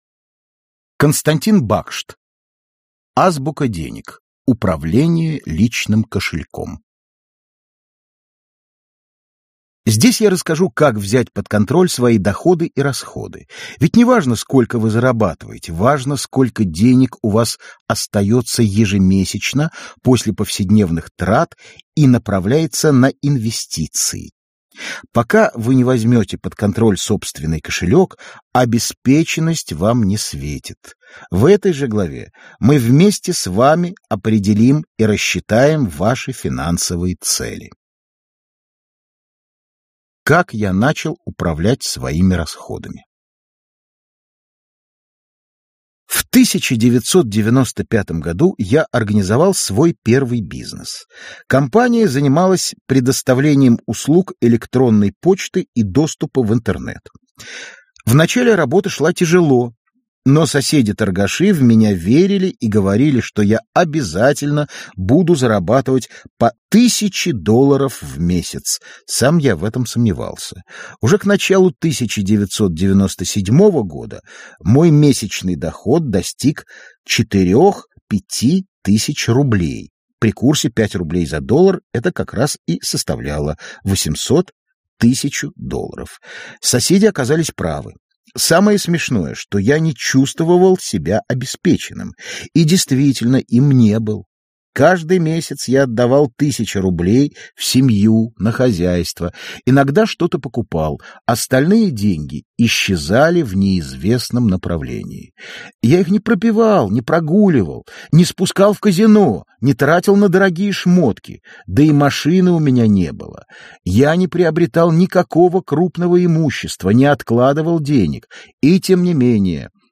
Аудиокнига Азбука денег: управление личным кошельком | Библиотека аудиокниг